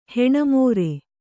♪ heṇa mōre